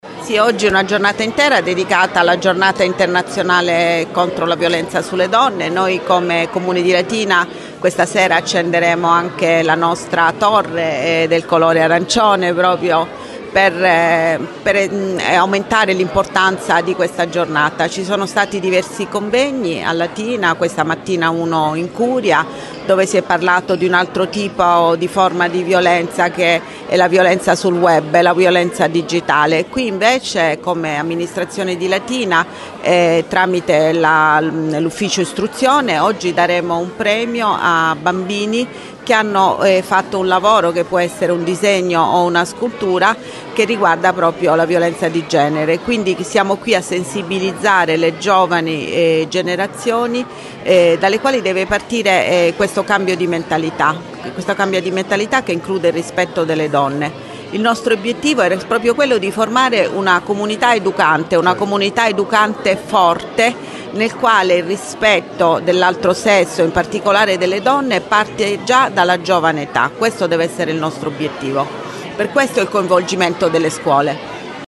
LATINA –  Si sono alternati  momenti dedicati alle  premiazioni del concorso Educare al rispetto e momenti di approfondimento sul tema della prevenzione della violenza di genere, nell’evento tematico promosso dal Comune di Latina per il 25 novembre che si è svolto al Teatro Ponchielli di Latina.
I saluti della Sindaca Celentano